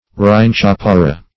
Search Result for " rhynchophora" : The Collaborative International Dictionary of English v.0.48: Rhynchophora \Rhyn*choph"o*ra\, n. pl.
rhynchophora.mp3